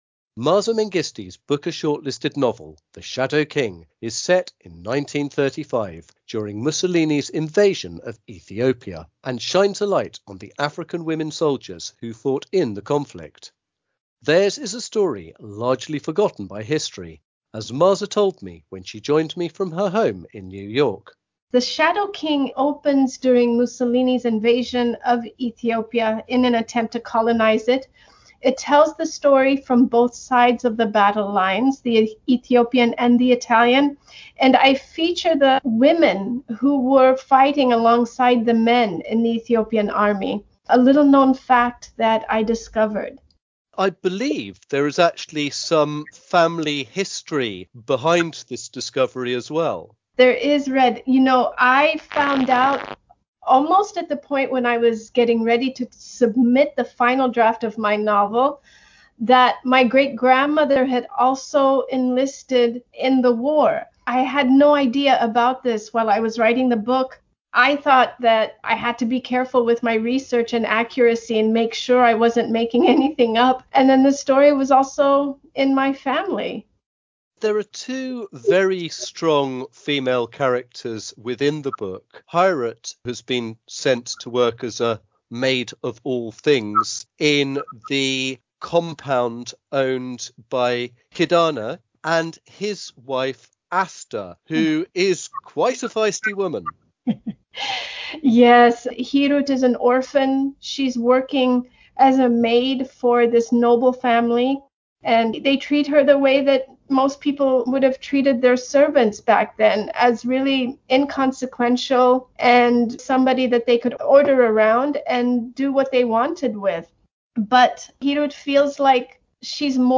Interview with Maaza Mengiste